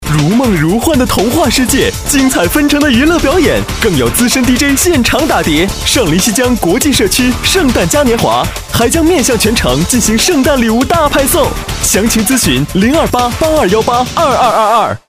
男国125_广告_节日_上林西江国际圣诞嘉年华_活力.mp3